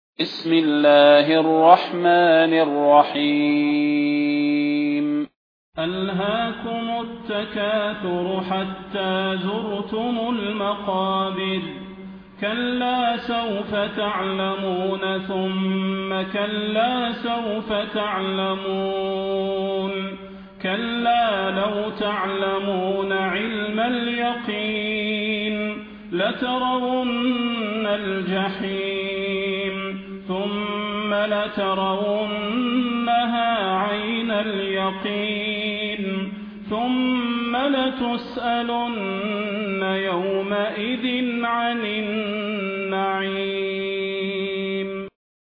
المكان: المسجد النبوي الشيخ: فضيلة الشيخ د. صلاح بن محمد البدير فضيلة الشيخ د. صلاح بن محمد البدير التكاثر